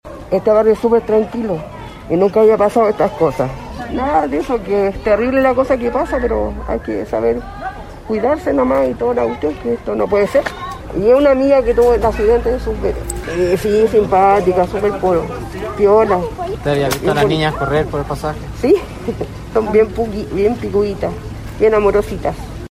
Una vecina, en tanto, lamentó lo ocurrido con las niñas.